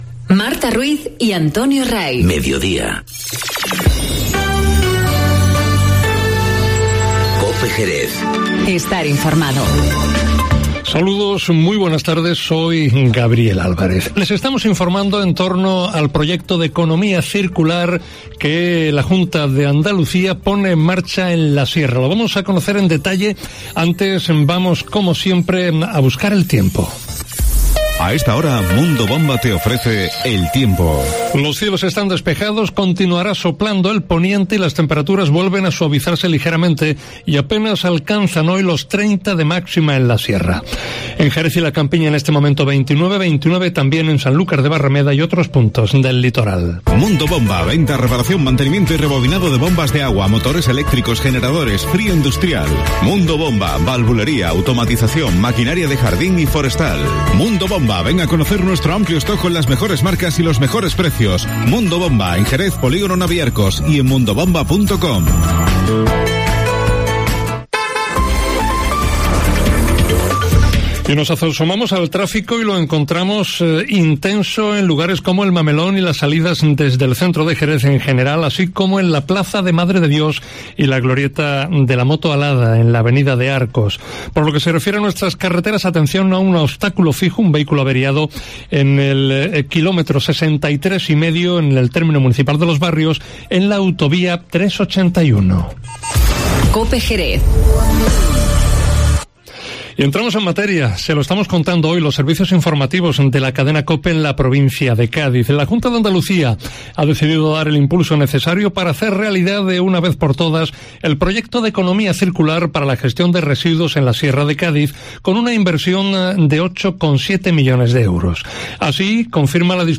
Informativo Mediodía COPE en Jerez 01-07-19